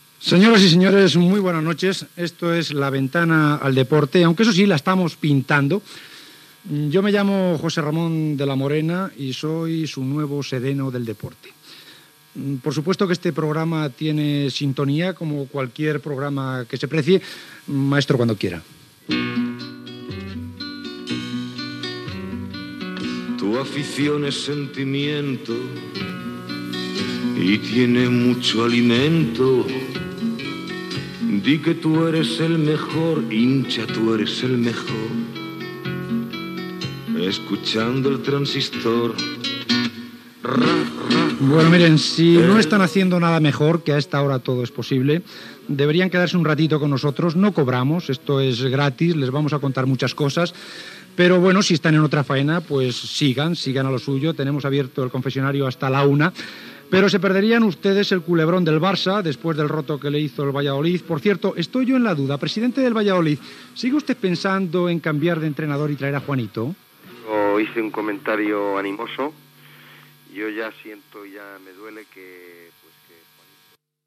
Salutació, sintonia i inici del programa
Esportiu
Primera edició del programa "La ventana del deporte" presentada per José Ramón de la Morena.